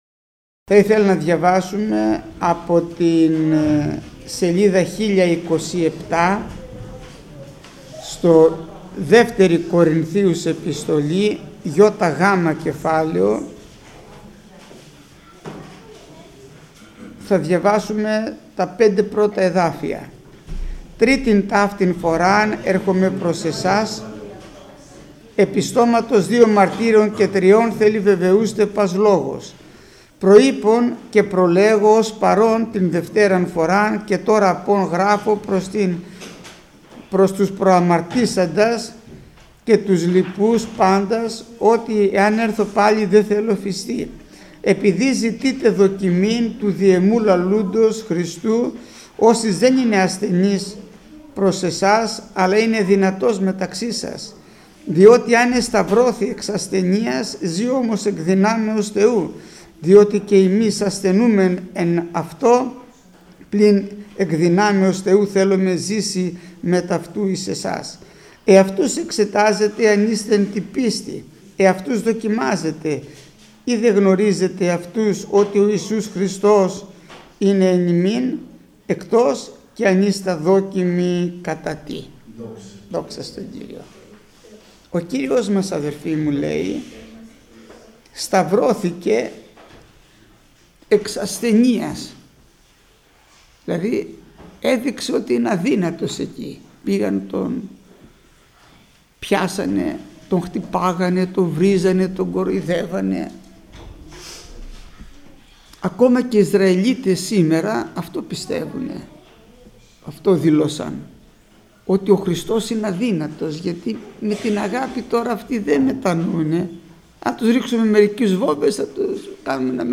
Μήνυμα πριν τη θεία κοινωνία